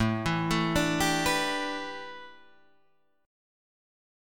A511 chord